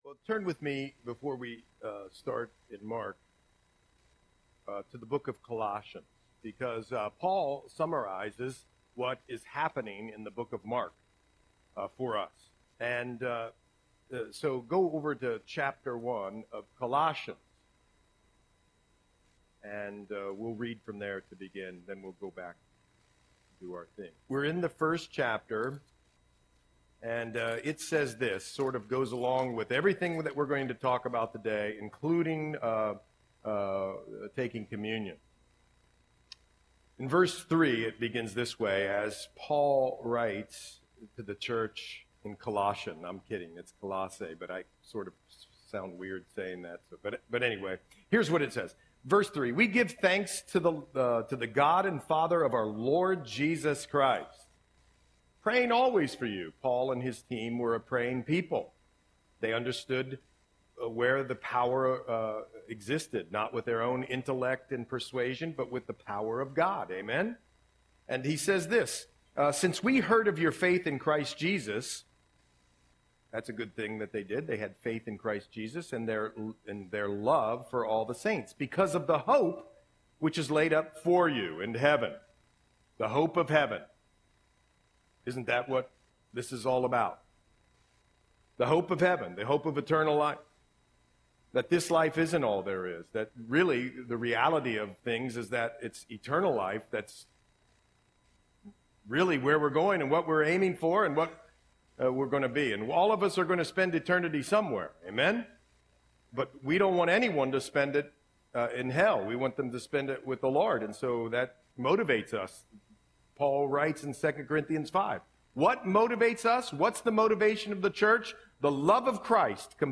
Audio Sermon - December 15, 2024